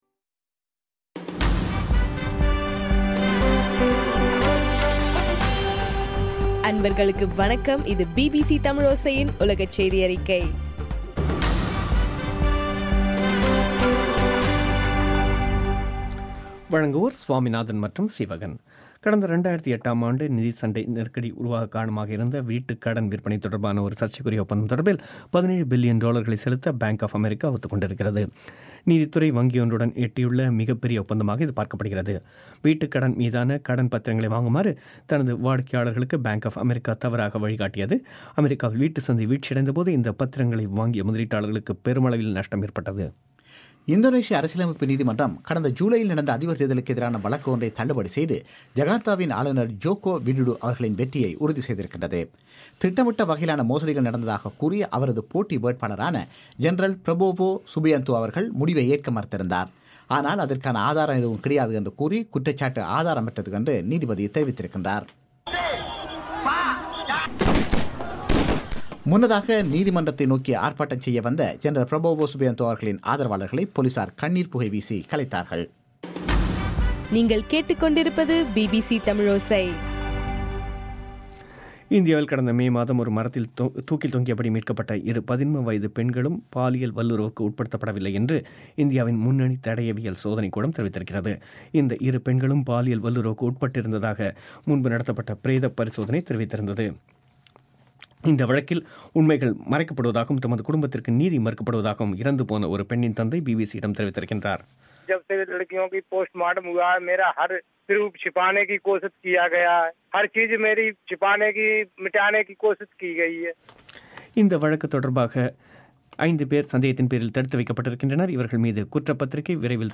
ஆகஸ்ட் 21 பிபிசியின் உலகச் செய்திகள்